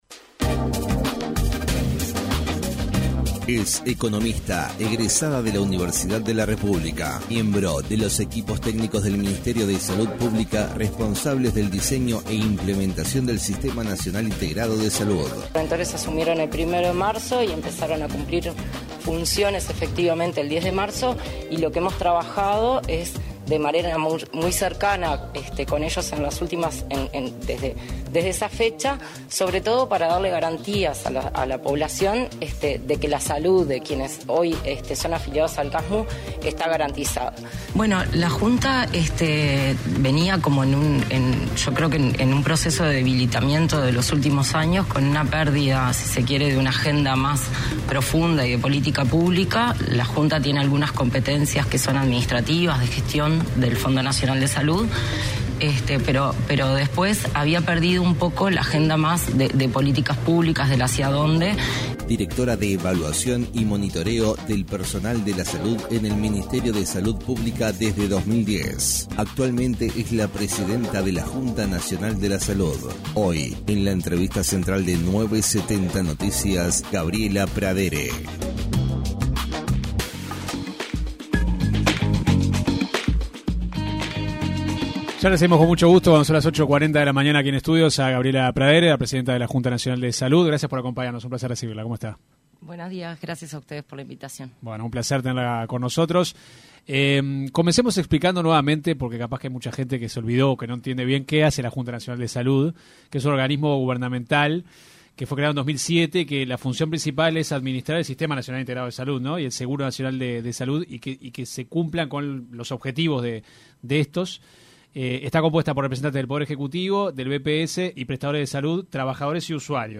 Escuche la entrevista completa aquí: La titular de la Junta Nacional de Salud, Gabriela Pradere, se refirió en una entrevista con 970 Noticias, a los servicios de salud de alta gama, donde los pacientes pagan un costo extra para ser atendidos antes.